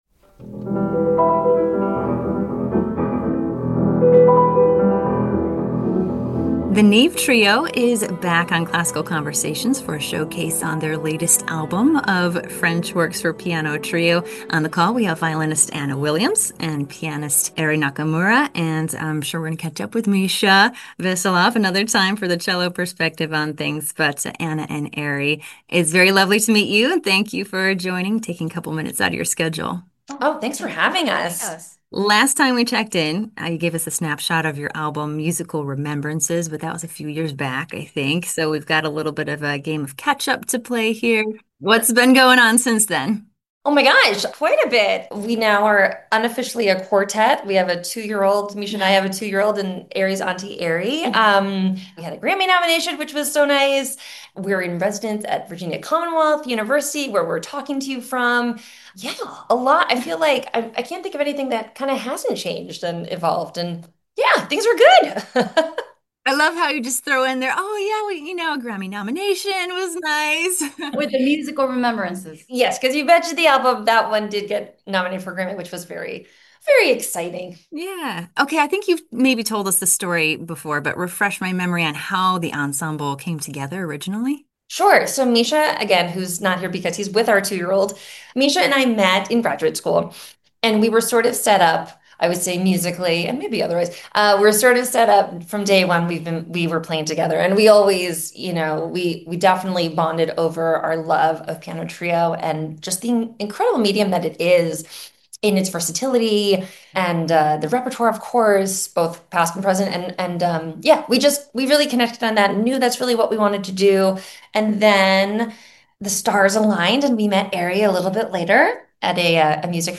Classical-Conversation-with-Neave-Trio-Export.mp3